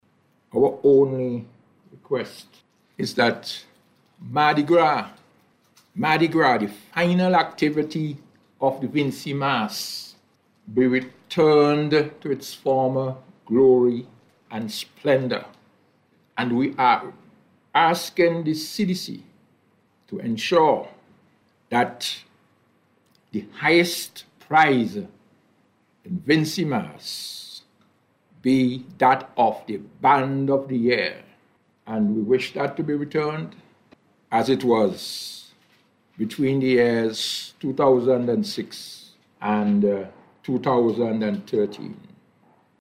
who was speaking during a News Conference hosted by the Carnival Development Corporation